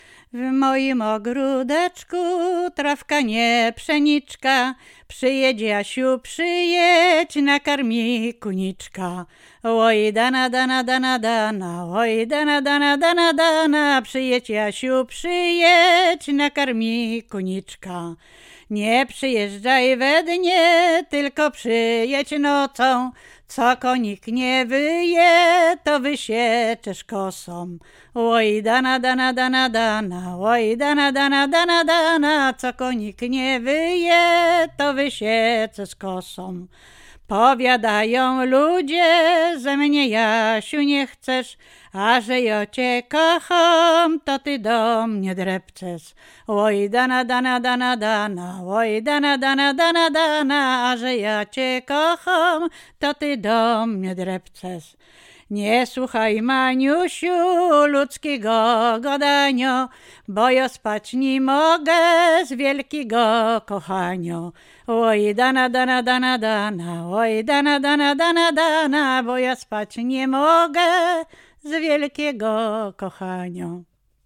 Łęczyckie
miłosne liryczne